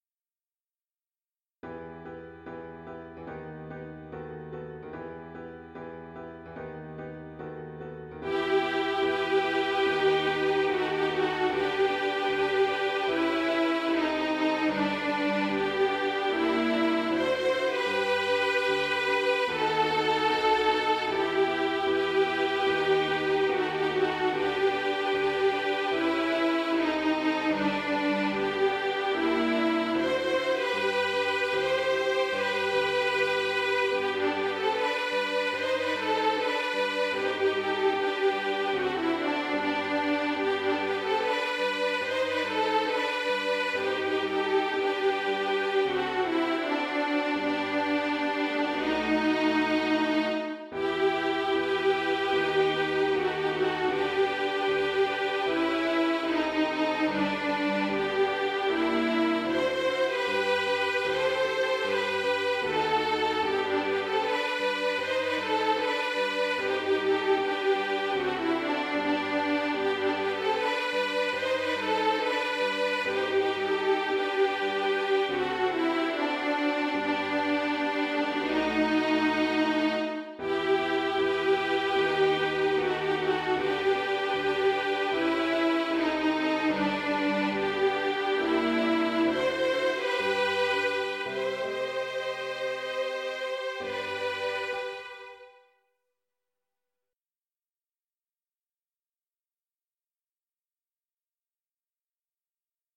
• Soprano II ONLY